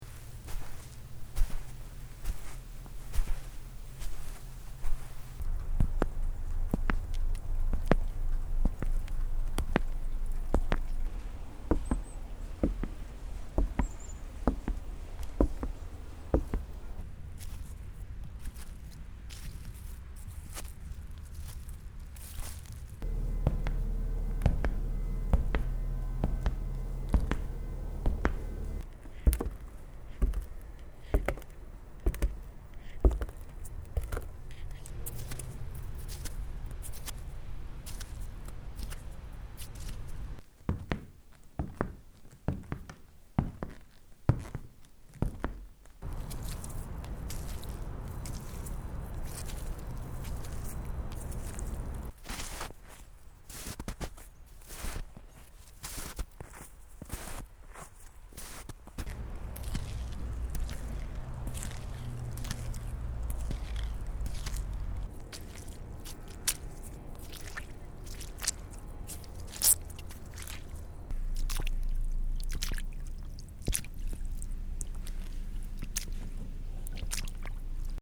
Footsteps over various terrain.